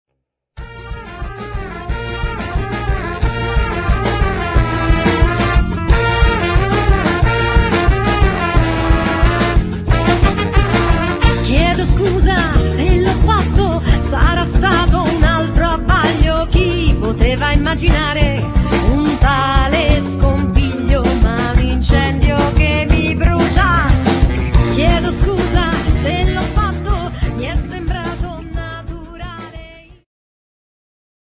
ジャンル Progressive
アコースティック
ローマ出身のアコースティック楽器と女性ヴォーカルによるグループ。
soprano, baritone sax and little flutes
drums and percussions